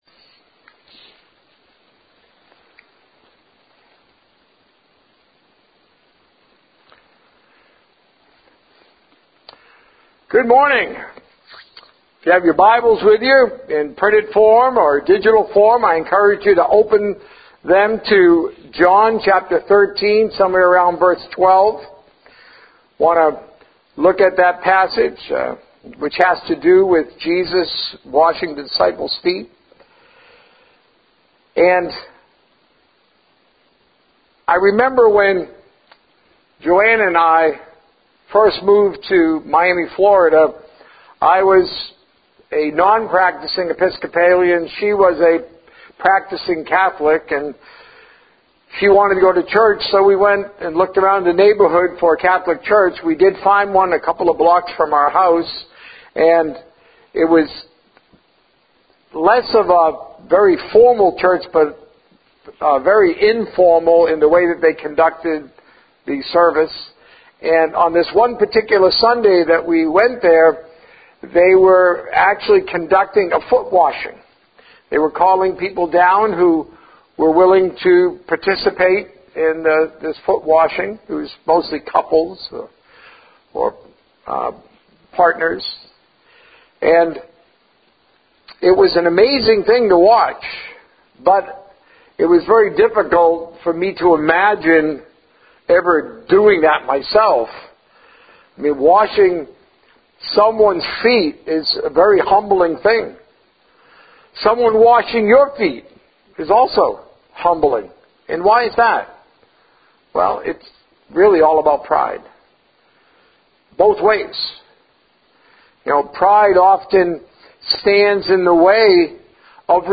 A message from the series "Time with Jesus."